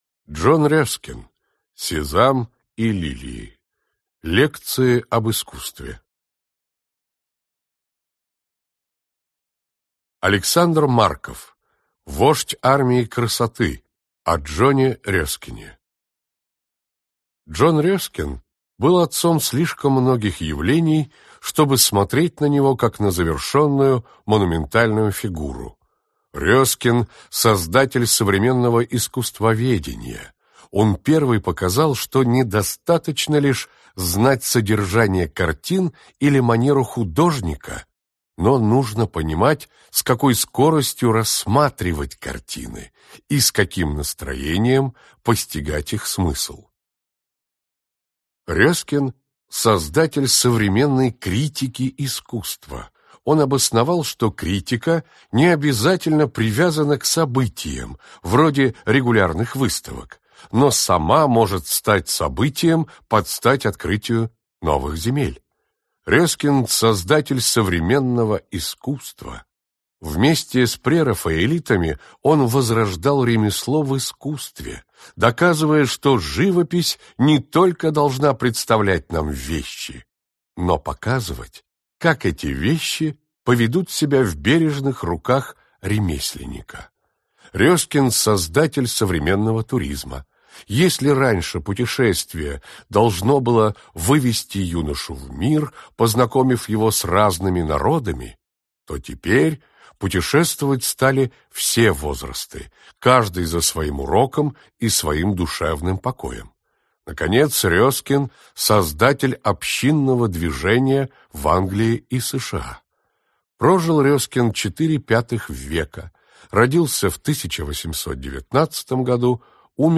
Аудиокнига Сезам и Лилии. Лекции об искусстве | Библиотека аудиокниг